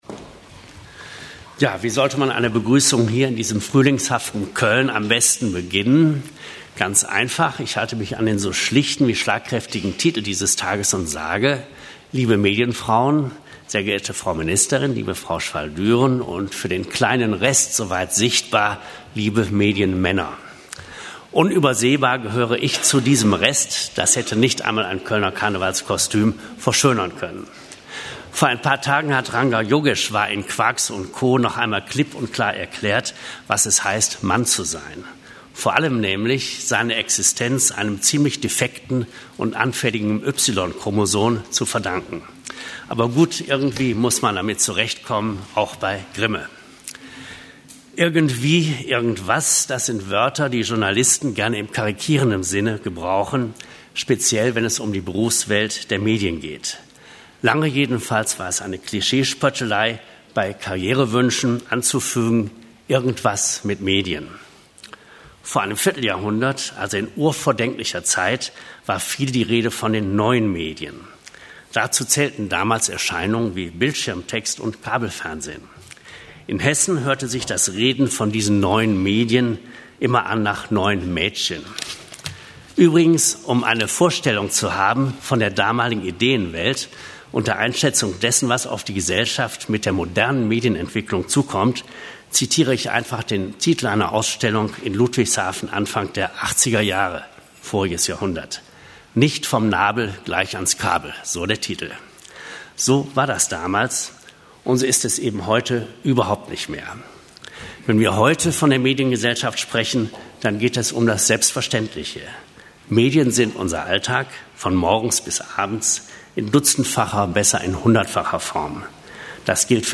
An dieser Stelle finden Sie unsere Audiodokumentation der Veranstaltung.
Begrüßung